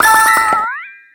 Cri de Ceriflor dans Pokémon X et Y.